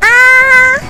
Worms speechbanks
ooff3.wav